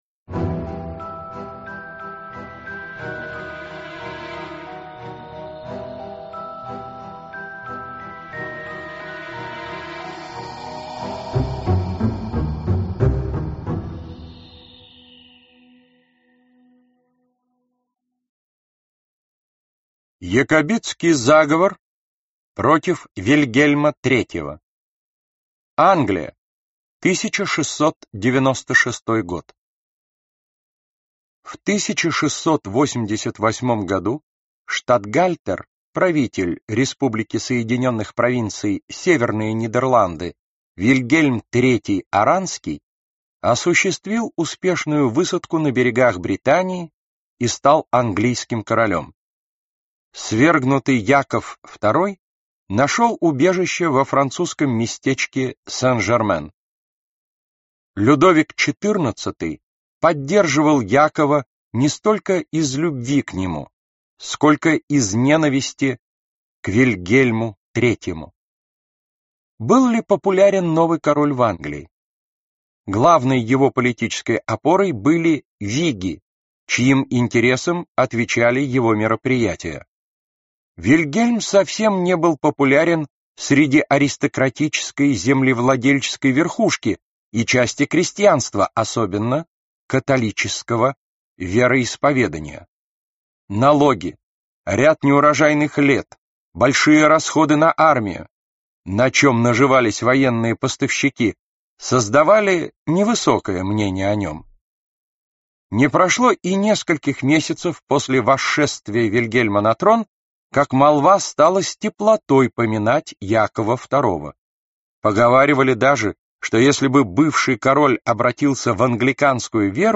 Аудиокнига Великие покушения. часть 1 | Библиотека аудиокниг